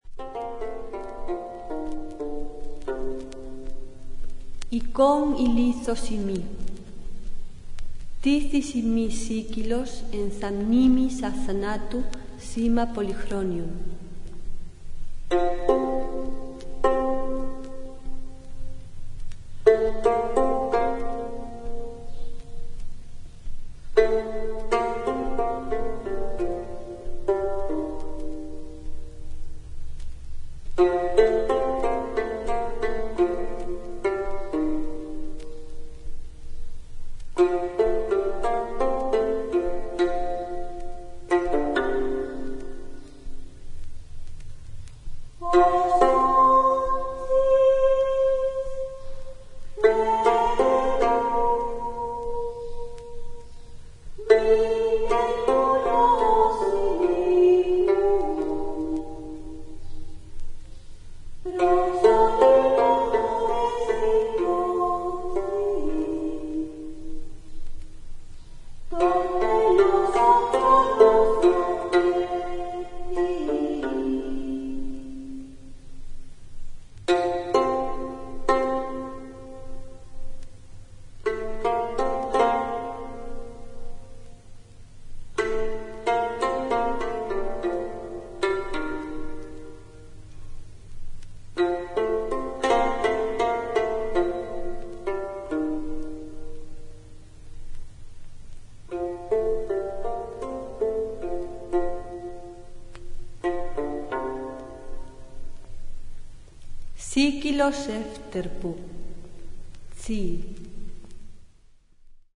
細部に至るまでをも表現するべく、当時の楽器36種類を復元・制作。